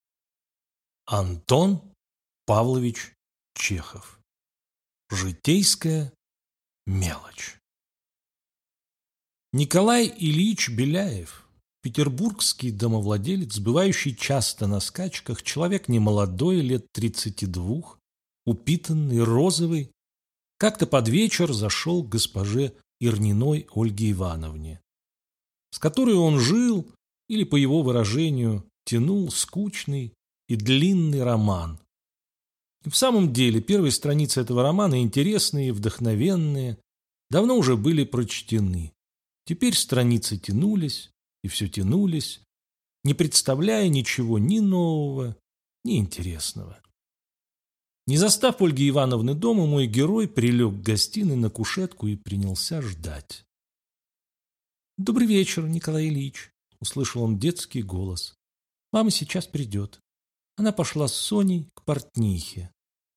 Аудиокнига Житейская мелочь | Библиотека аудиокниг
Прослушать и бесплатно скачать фрагмент аудиокниги